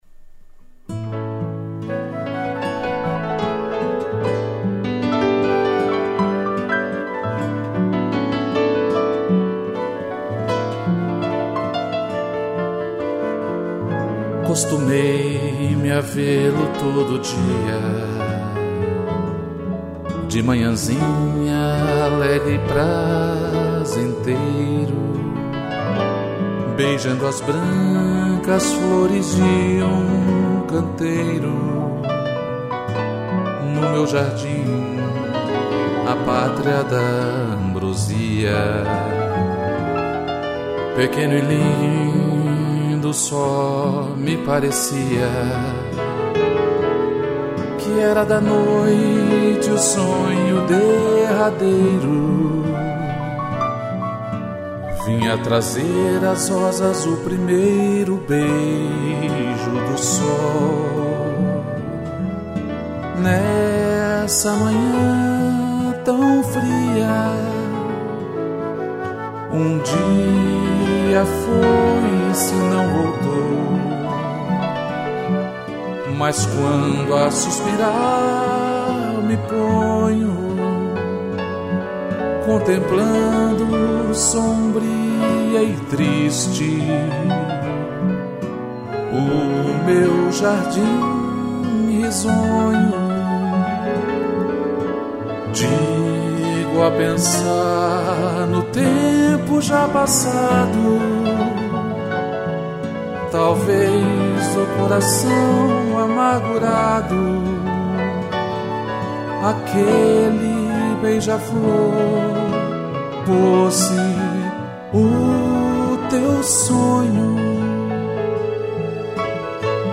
voz e violão
piano, cello e flauta